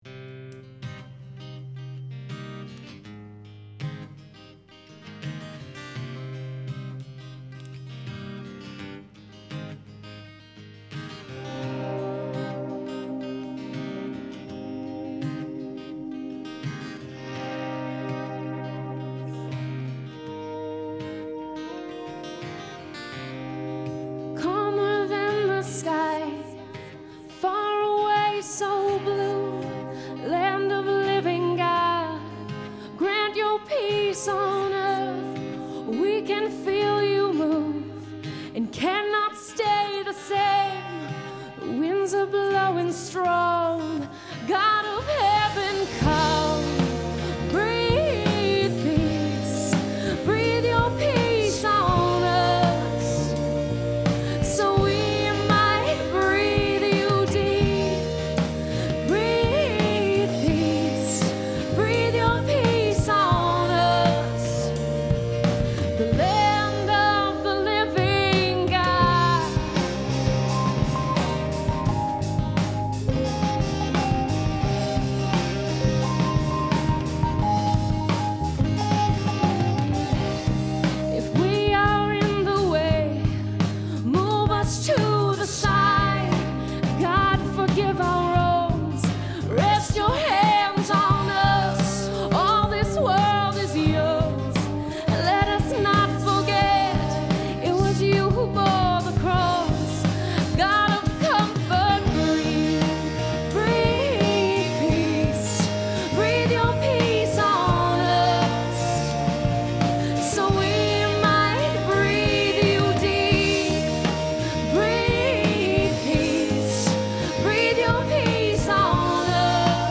Wayfaring Stranger
Performed live at Terra Nova - Troy on 12/6/09.